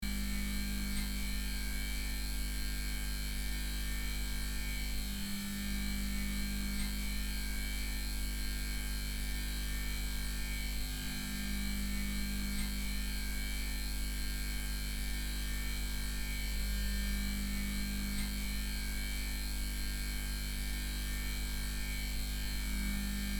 Broken-lamp-light-buzzing-loop.mp3